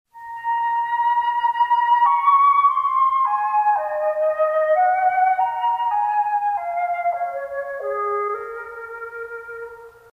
Description: Home page intro music